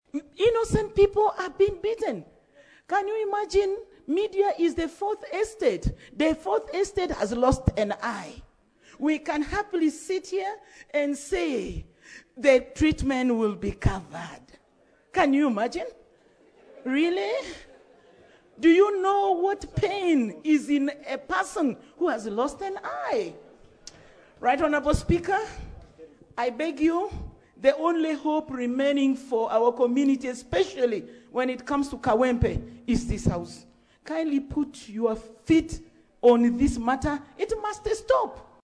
Hon. Obigah (standing) expressed fears about the excess brutality by the security agencies
Hon. Rose Obigah (NRM, Terego District Woman Representative) criticised the militarisation of elections.